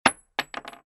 Гильза падает на пол из дерева